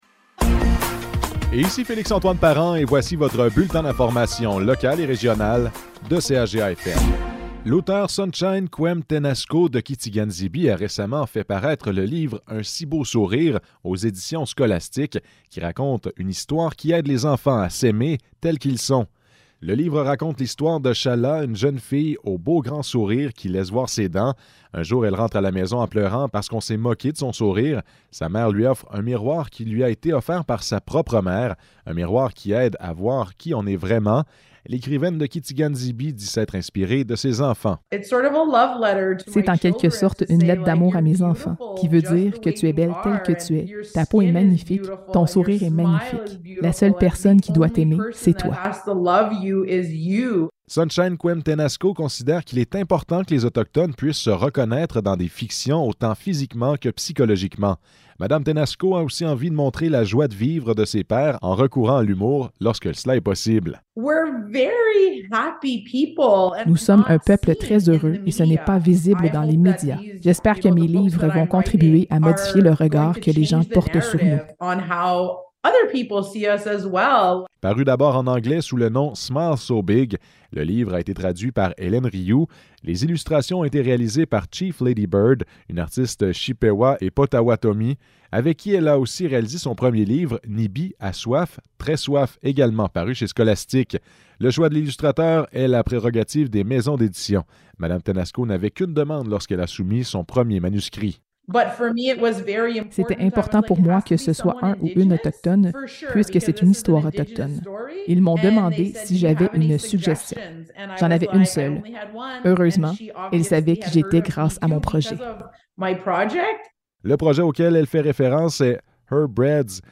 Nouvelles locales - 11 avril 2023 - 12 h